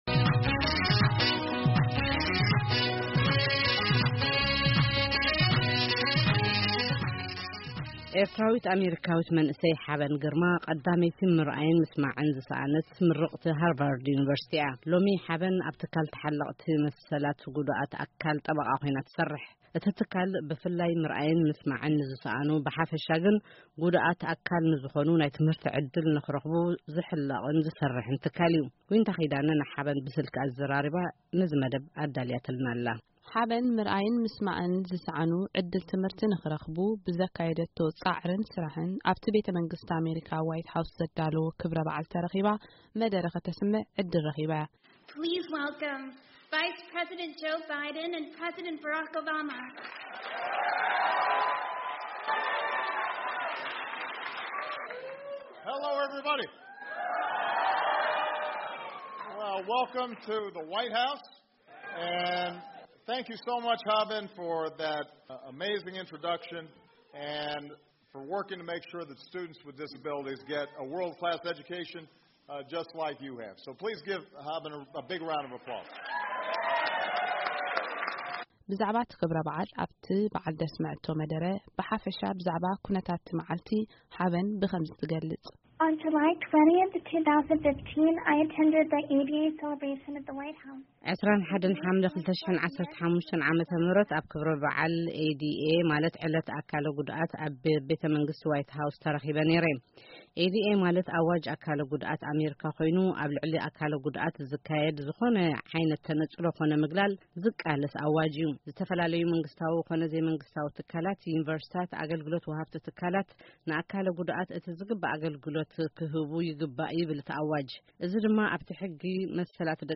ቃለ-መጠይቕ ምስ ቀዳመይቲ ምርኣይን ምስማዕን ዝሰአነት ምርቕቲ ሃርቨርድ ዩኒቨርሲቲ ሓበን ግርማ